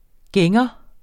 Udtale [ ˈgεŋʌ ]